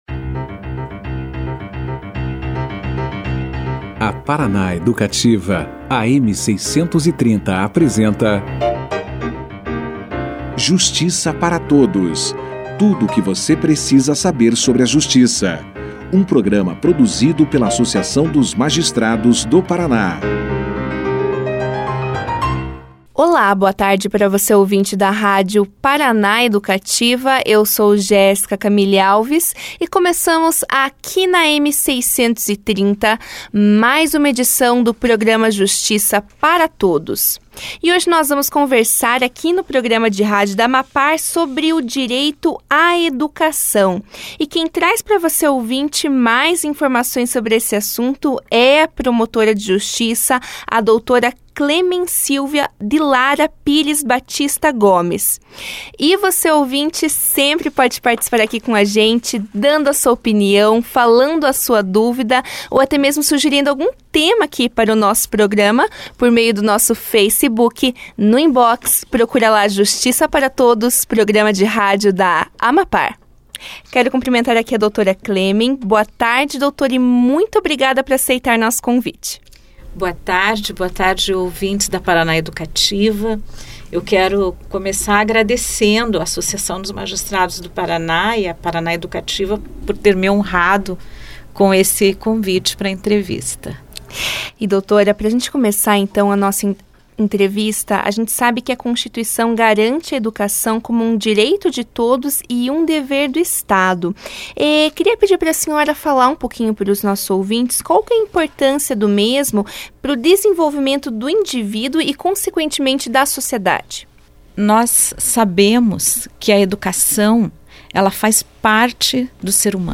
A promotora de Justiça, Clemen Silva de Lara Pires Batista Gomes conversou com o programa Justiça para Todos, na quarta-feira (26), sobre o direito à educação.